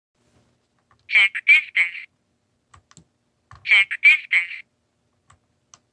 Check Distance all in its annoying you don’t know what your doing “voice” thankfully you can turn it off.
check_distance.m4a